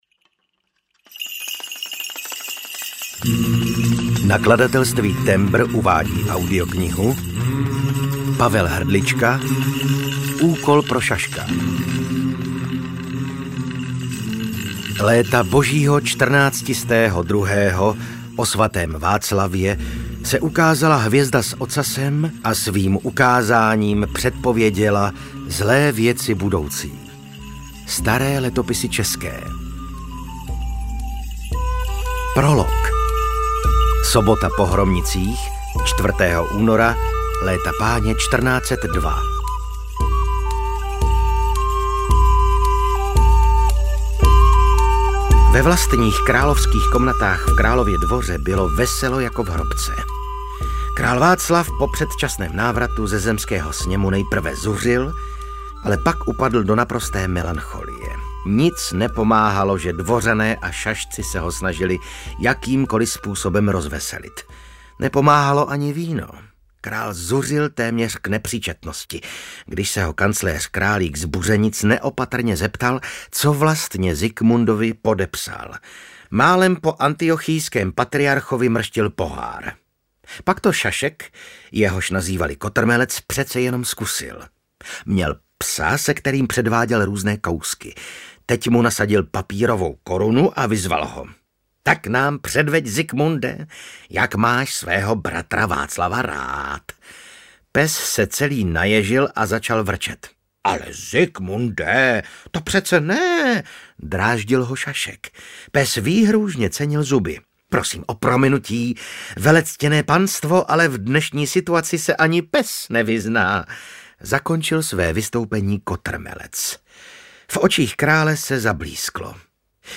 Úkol pro šaška audiokniha
Ukázka z knihy
• InterpretVasil Fridrich